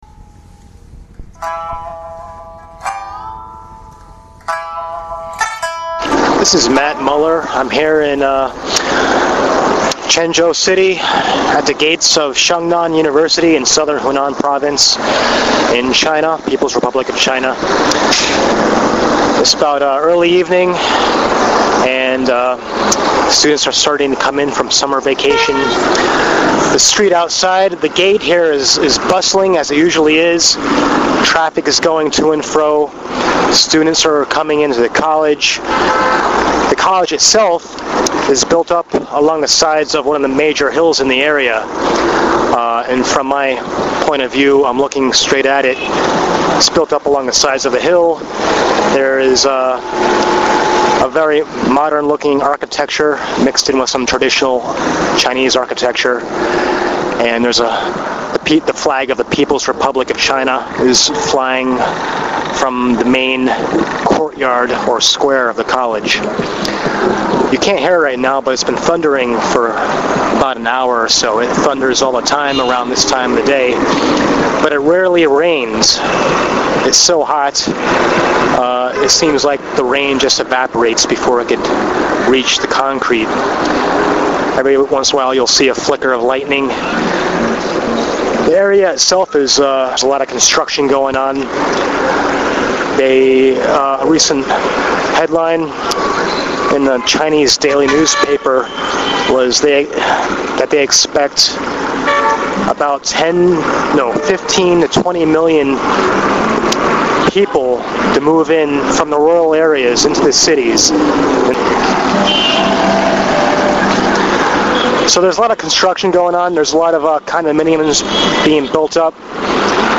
As I walk the streets outside the university, cars blow horns and a busload of people compete for the best positions to see the Westerner.